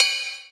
• Crash Single Hit D Key 01.wav
Royality free crash cymbal sound sample tuned to the D note. Loudest frequency: 3890Hz
crash-single-hit-d-key-01-mWL.wav